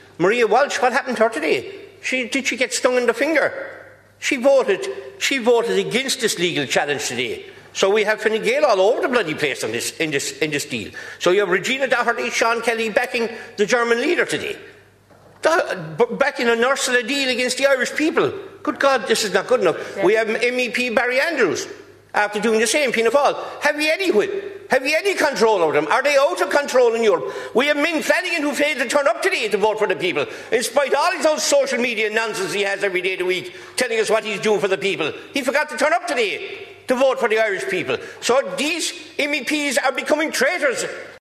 Speaking in the Dáil Deputy Michael Collins, leader of Independent Ireland hit out at MEPs who voted against this move, including Midlands North West MEPs Luke Ming Flannagan and Maria Walsh……………